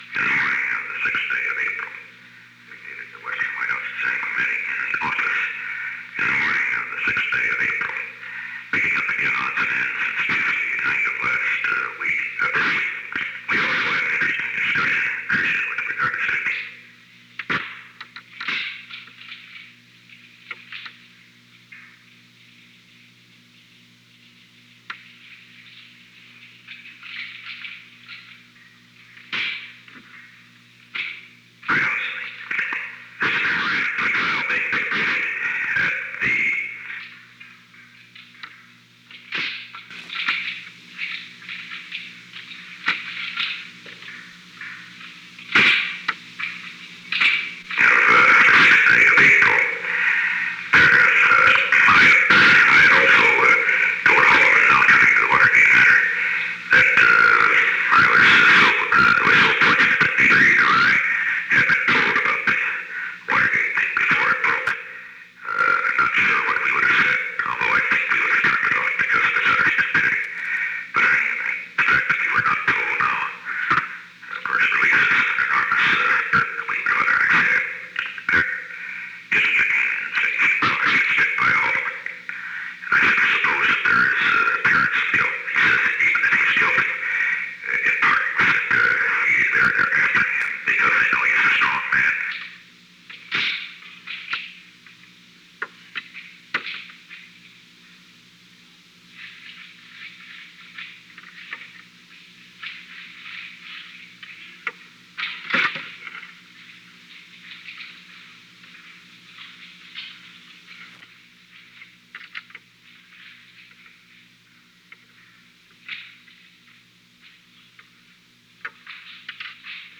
• President Richard M. Nixon
Location: Oval Office
The President played portions of a previously recorded dictabelt tape.